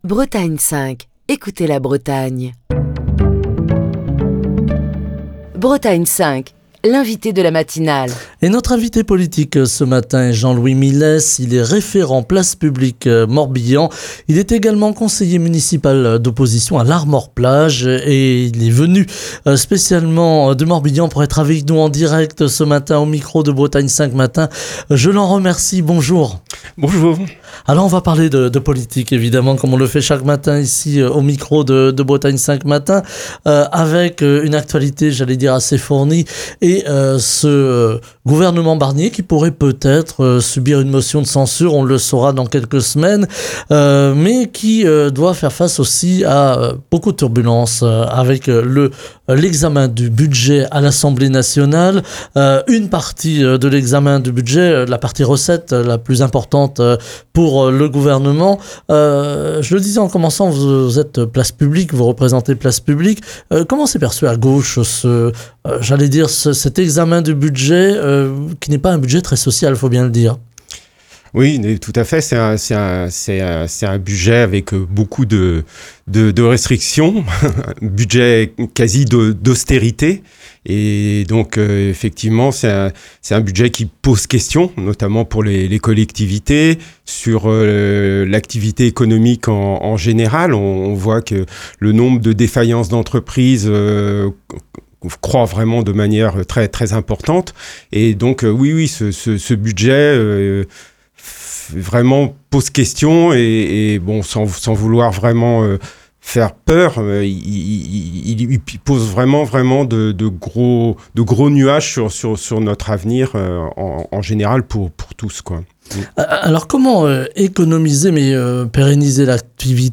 Ce mardi, Jean-Louis Milès, référent Place Publique Morbihan, conseiller municipal d'opposition à Larmor-Plage est l'invité politique de Bretagne 5 matin. Au micro de la matinale, Jean-Louis Milès livre ses commentaires autour de l'examen du budget qui s'annonce compliqué l'Assemblée nationale.